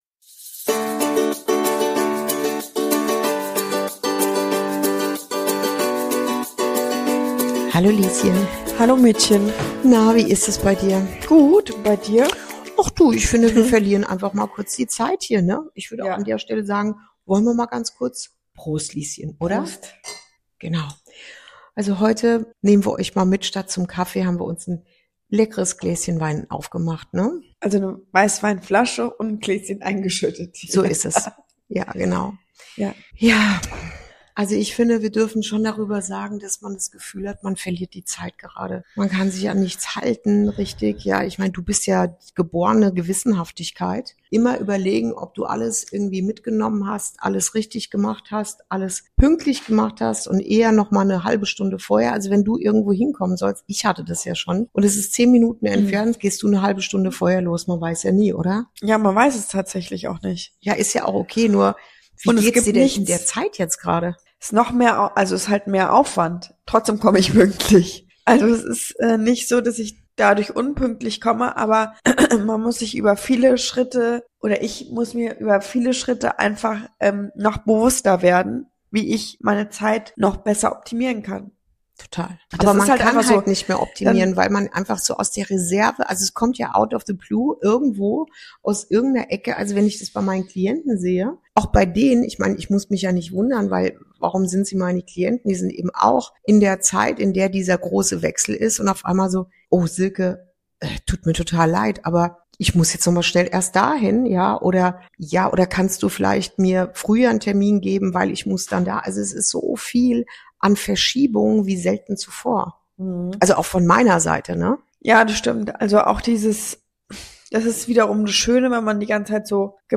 Diese Folge ist leiser.